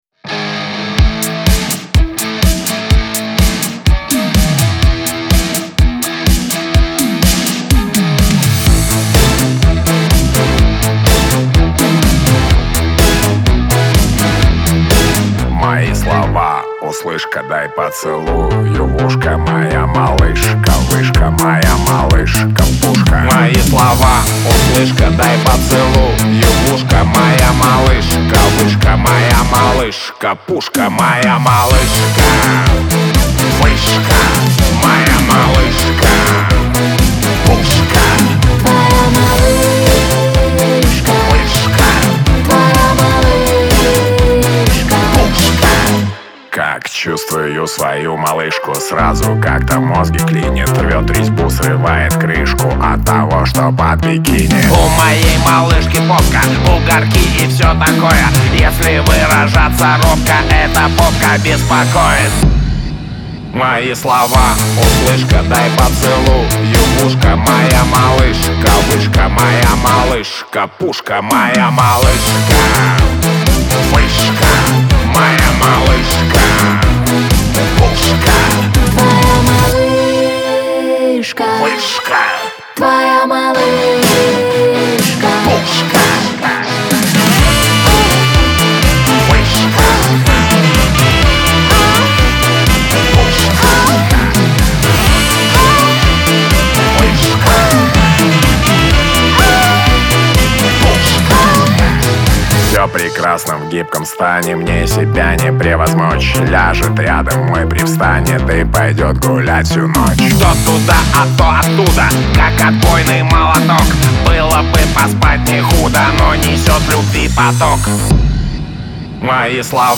эстрада , pop , Веселая музыка